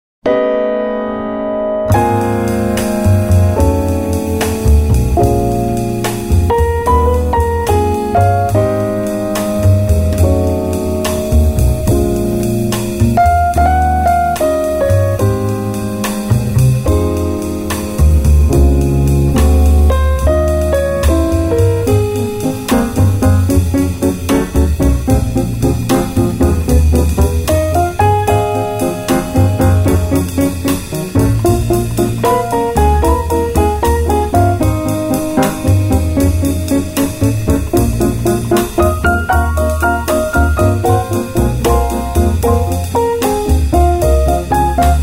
piano
bass
drums
Recorded at Avatar Studio in New York on April 26 & 27, 2010